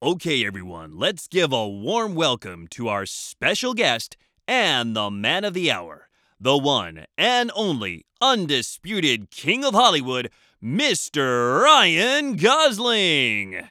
バイリンガル司会・MC
ボイスサンプル
ゲスト紹介　MC